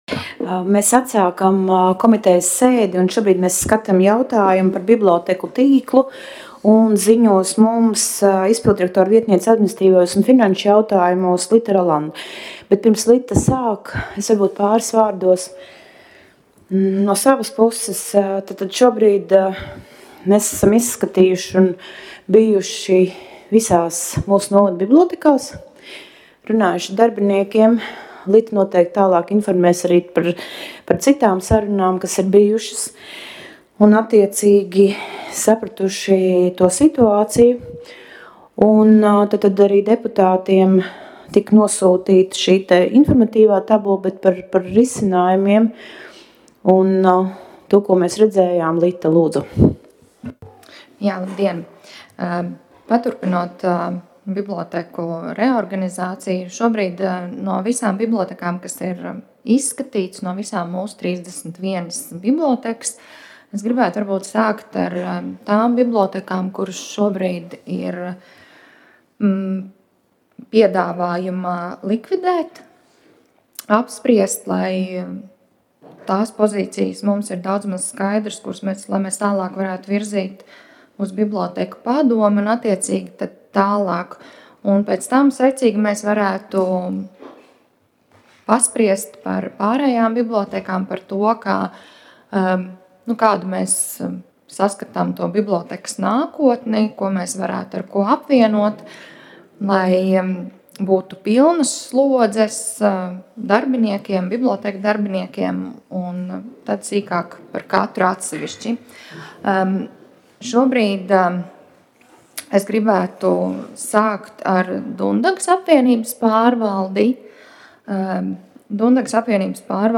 Komitejas sēdes audio